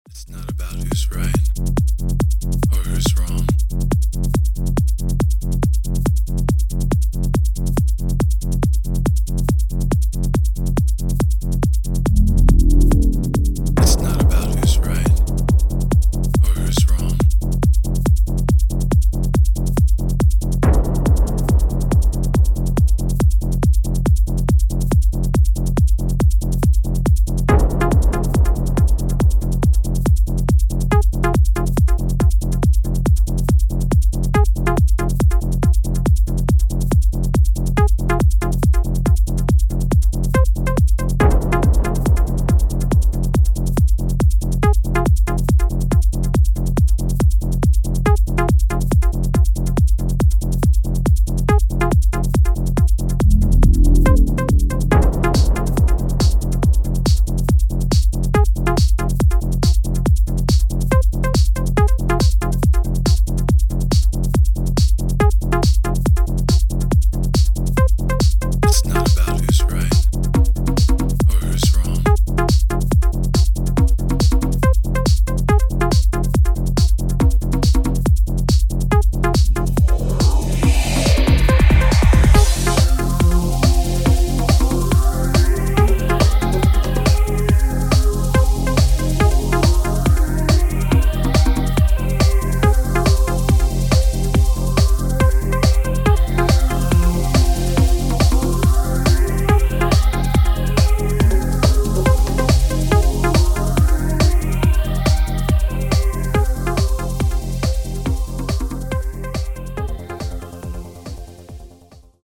die songs 8 bis 13 sind techno-tracks (140 bpm)